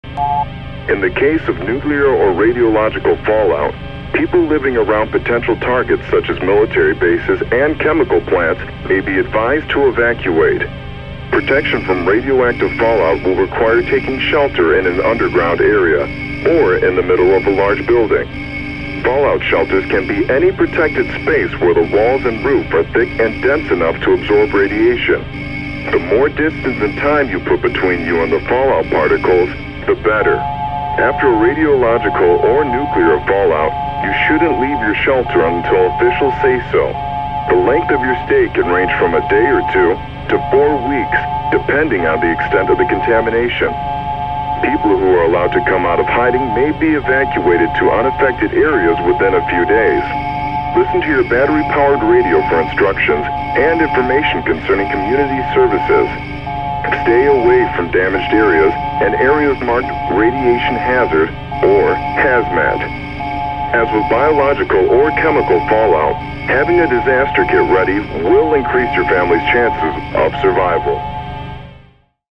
Nuclear Broadcast Message
SFX
yt_IMwfNjhWCR0_nuclear_broadcast_message.mp3